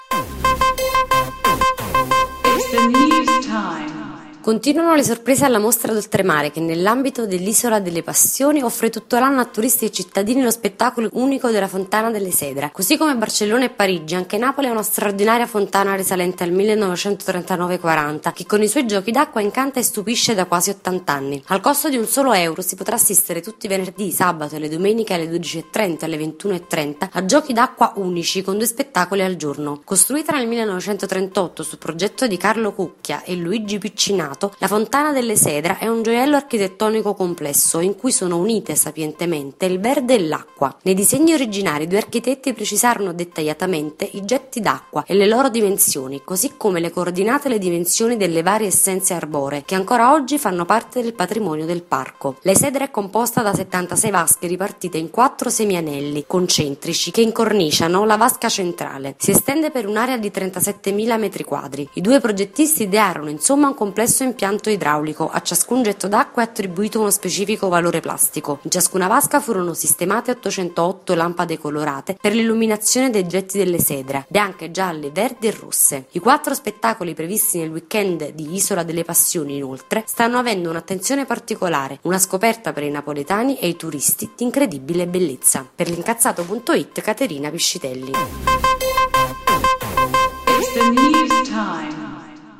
Se vuoi ascoltare l’articolo letto dalle nostre redattrici clicca qui